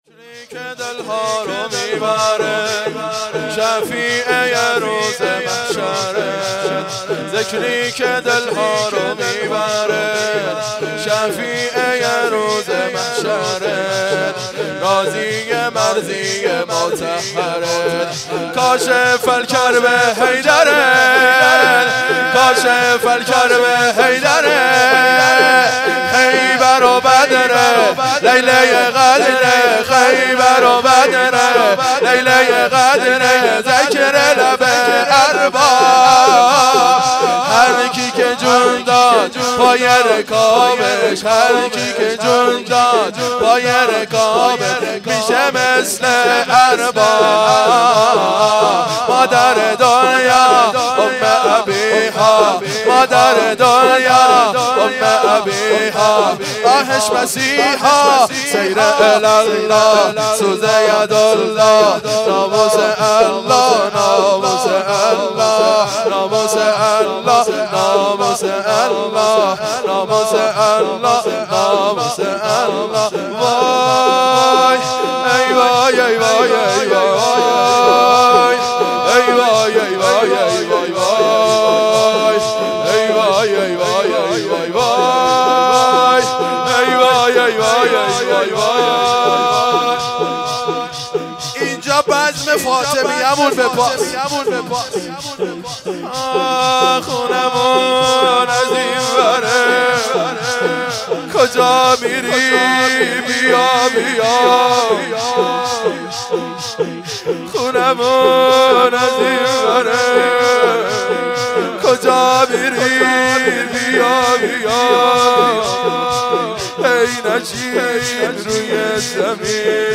وفات-حضرت-معصومه-شور.mp3